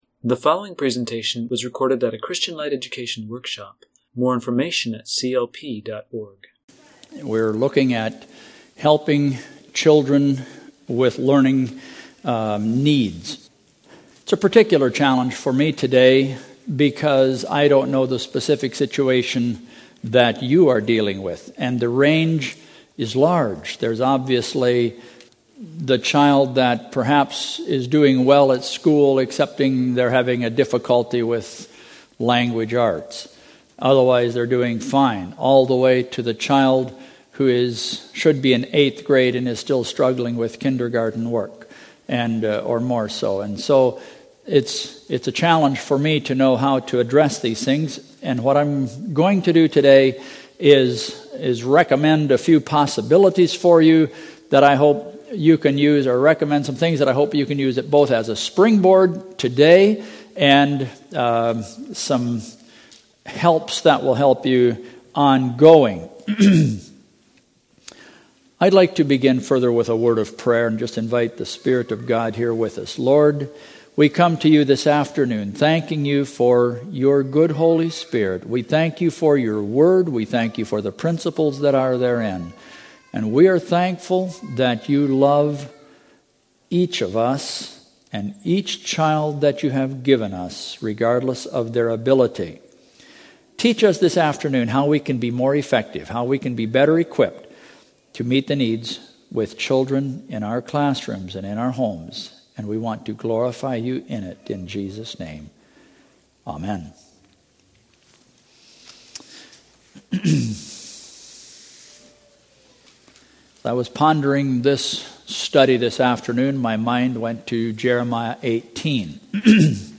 Home » Lectures » Serving Students with Learning Needs